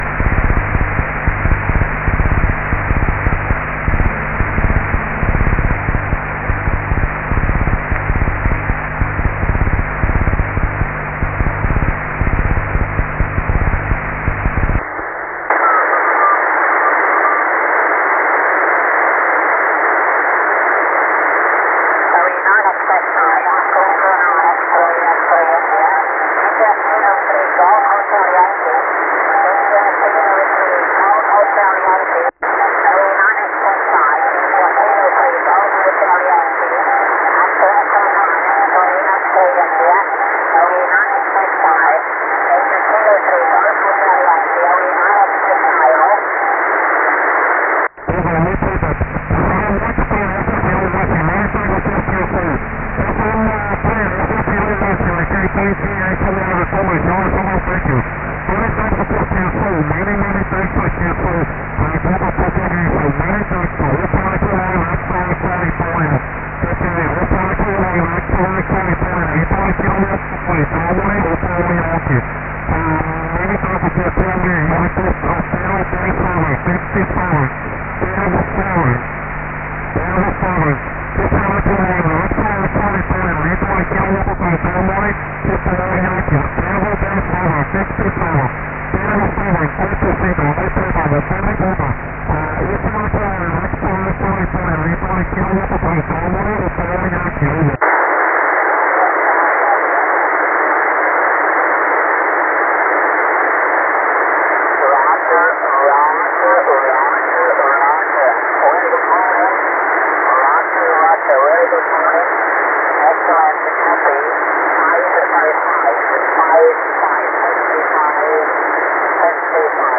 REGISTRAZIONI DEGLI ECHI LUNARI DEI BEACONS E DEI QSO